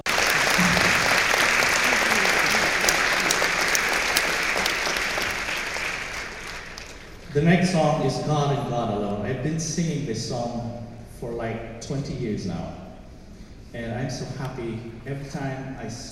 Last night, I was obliged to attend Rhythm of Life, a charity concert in support of the Adventist Home for the Elders, Adventist Nursing and Rehabilitation Centre and Adventist Community Services.
I took the opportunity to test out the in-ear binaurals I got from The Sound Professionals and borrowed an old MiniDisc player.
It being the first time I’ve recorded a live show, I missed out the beginning of the first song. Which was fine anyway, because the levels were too high and the sound got distorted. Then I might have gotten too conservative and made the levels too low.
These songs are best heard on headphones to hear the direction of chatter, laughter and so on. You can even pick me up sniffing.
There’s some distortion at the loud parts.